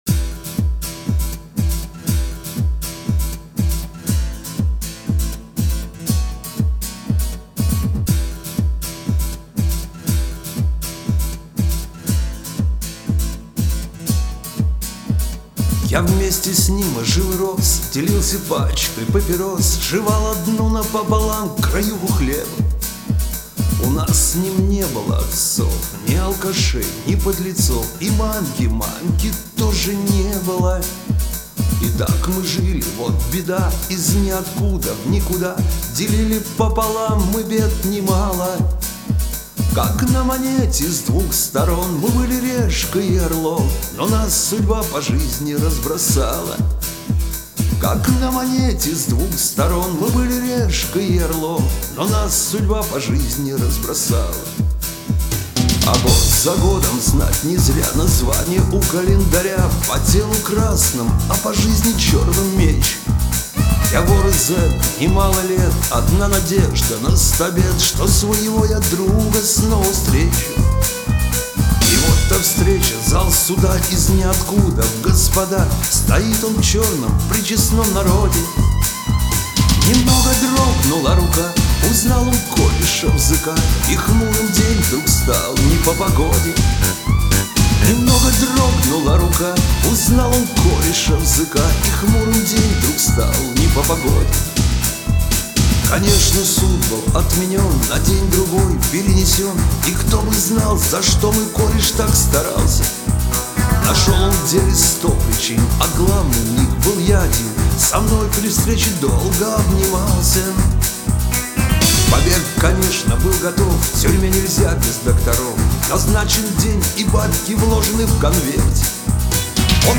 Шансон
Очень яркий молодой и талантливый автор, исполнитель.